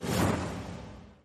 Feature_Buy_Cancel_Sound.mp3